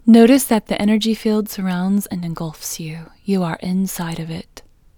OUT Technique Female English 4